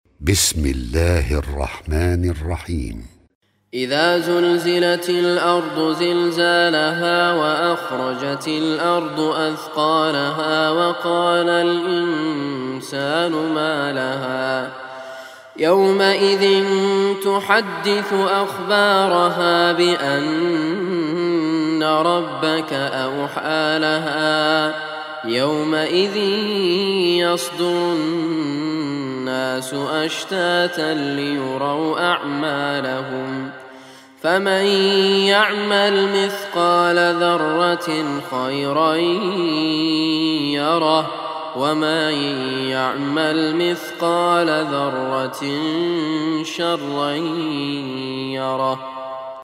Surah Al-Zalzalah Online Recitation by Raad Kurdi